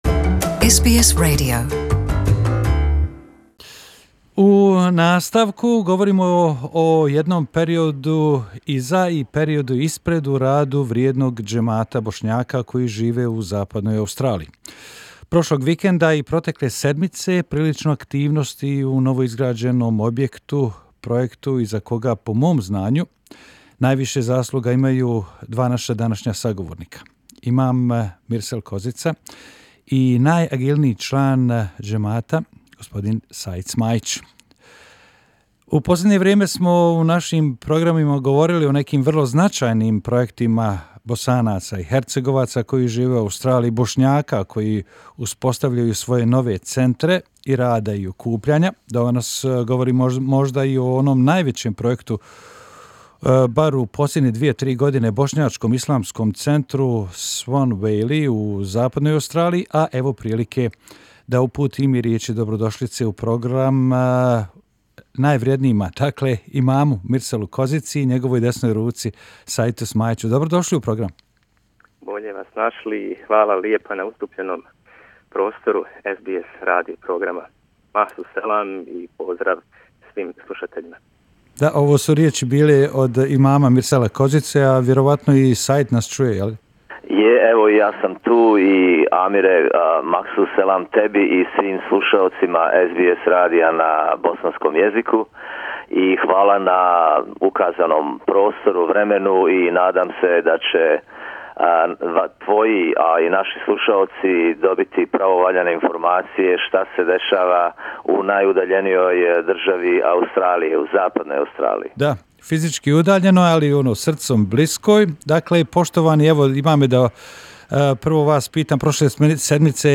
U razgovoru za naš radio